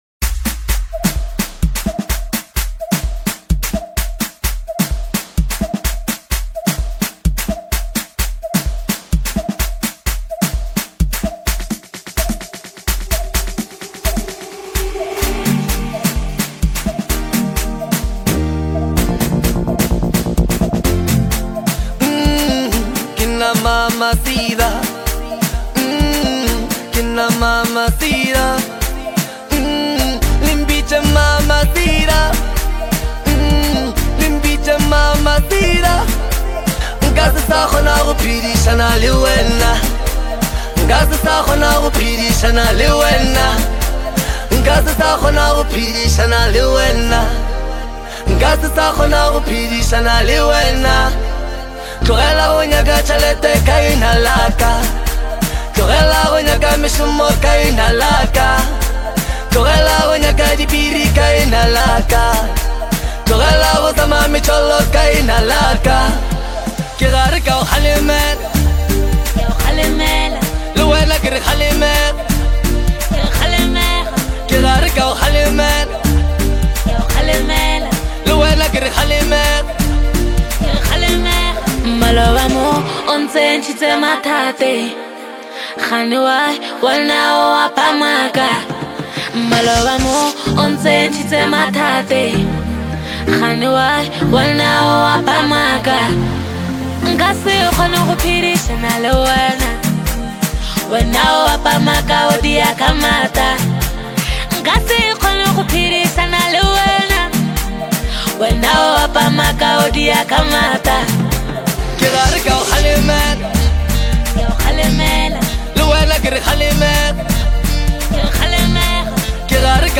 the gentle,melodic production that cradles the vocals